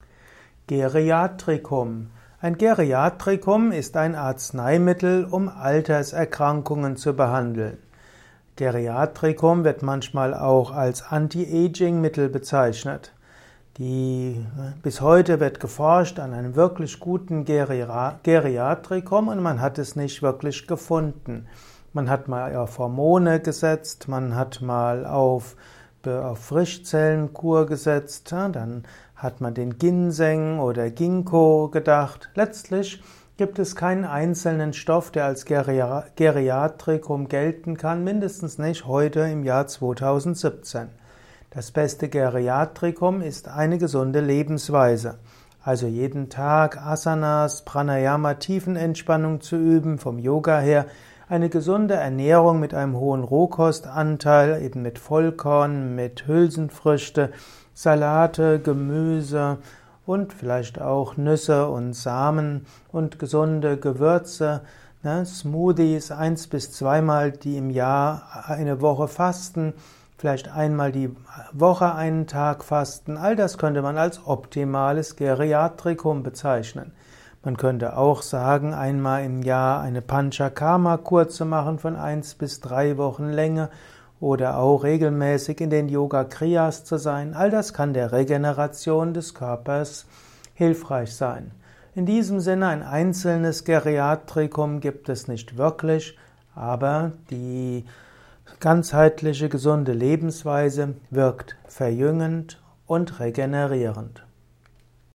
Vortragsaudio rund um das Thema Geriatrikum. Erfahre einiges zum Thema Geriatrikum in diesem kurzen Improvisations-Vortrag.